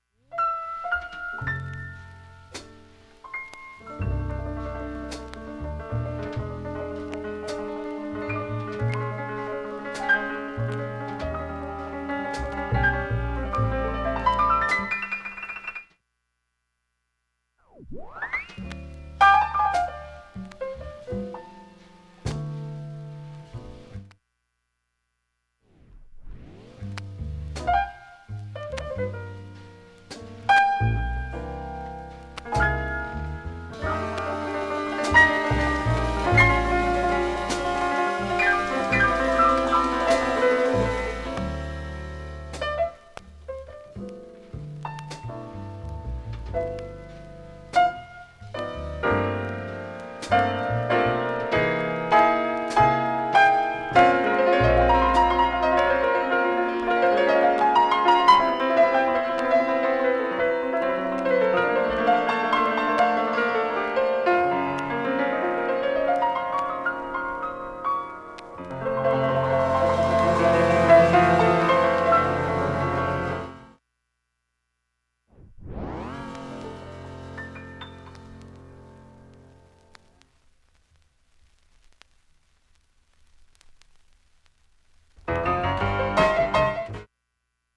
３回と４回と２回のかすかなプツが出ますが、
聴き取りしにくいレベルです。
レコードはモノラル盤です。